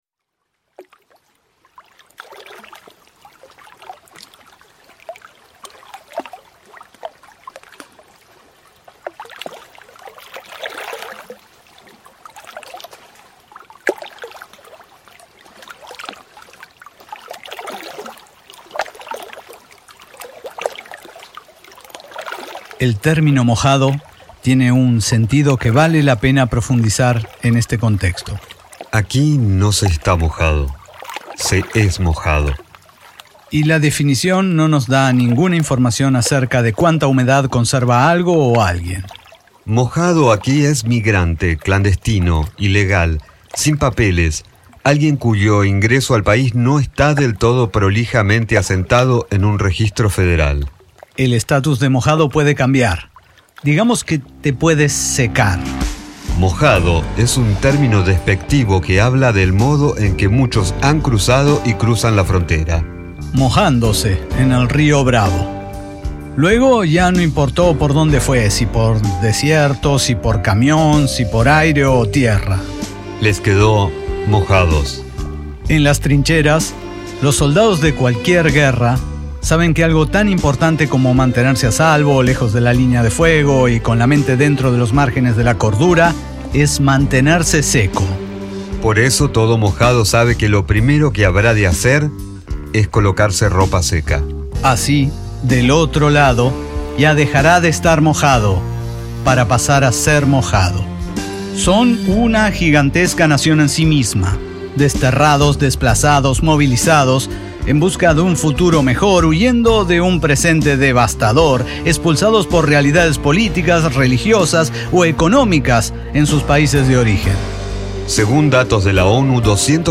Narración en primera persona de la odisea de cruzar ilegalmente la frontera más transitada del mundo, México - USA.